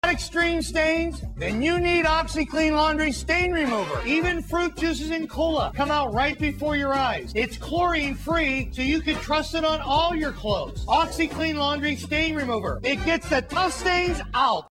Classic Billy Mays Oxi Clean Commercial sound effects free download